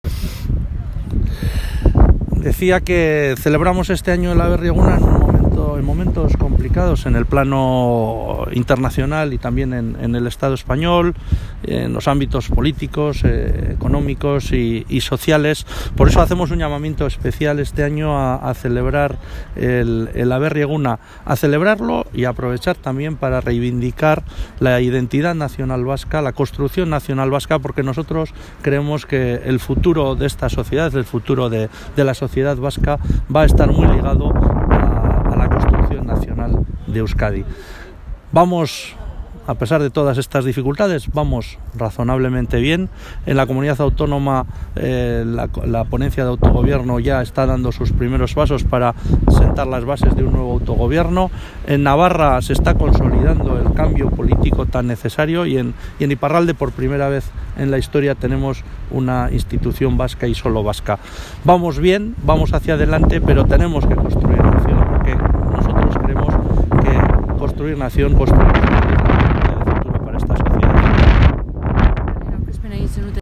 Andoni Ortuzar en Bizkargi 31/03/2018